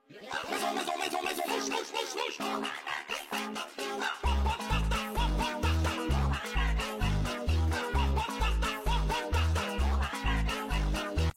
pomme pomme tarte tarte Meme Sound Effect